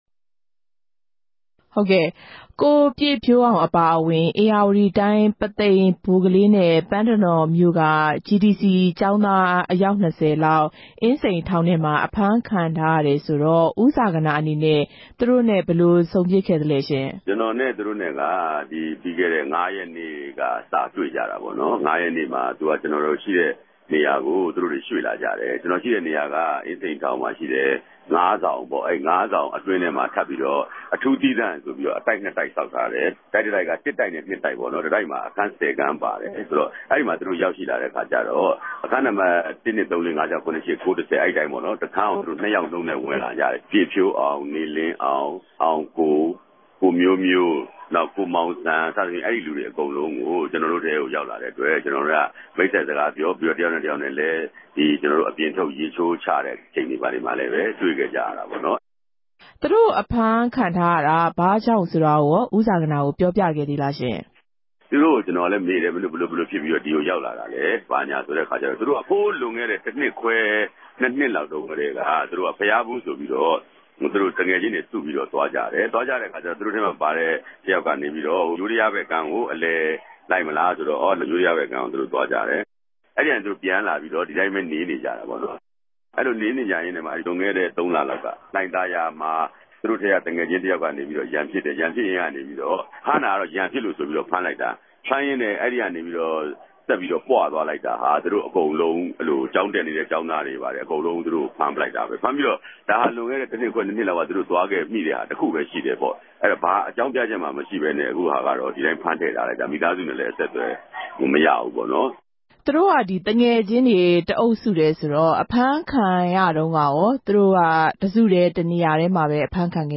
ဟာသ သရုပ်ဆောင် ဦးဇာဂနာ ခေၞ ဦးသူရနဲႚ ဆက်သြယ်မေးူမန်းခဵက်